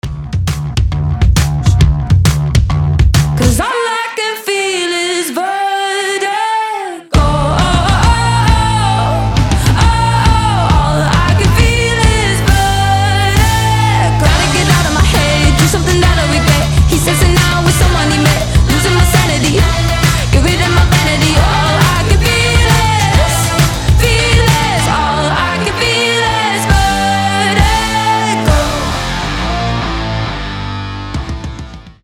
• Качество: 320, Stereo
гитара
женский голос
Pop Rock
alternative
динамичные
озорные
барабаны